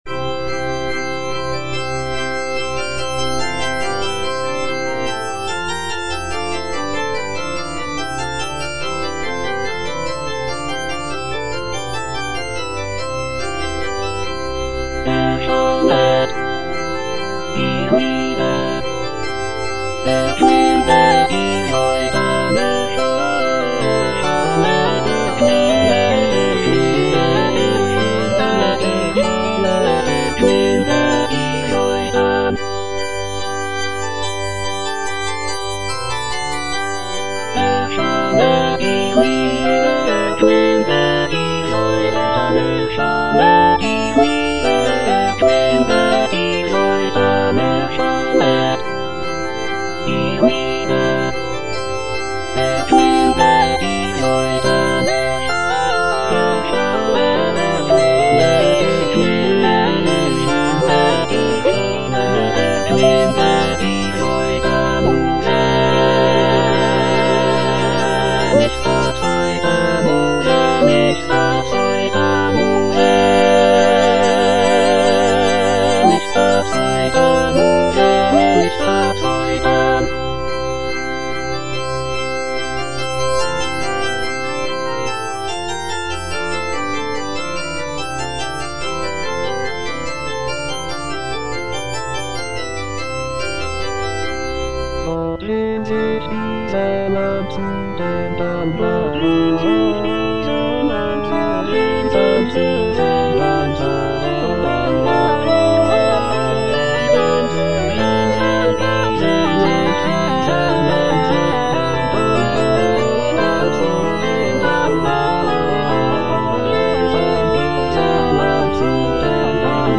Choralplayer playing Cantata
J.S. BACH - CANTATA "ERSCHALLET, IHR LIEDER" BWV172 (EDITION 2) Erschallet, ihr Lieder (All voices) Ads stop: auto-stop
It features a jubilant opening chorus, expressive arias, and intricate chorales.
The music is characterized by its lively rhythms, rich harmonies, and intricate counterpoint.